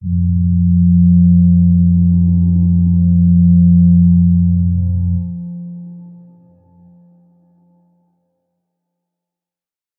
G_Crystal-F3-mf.wav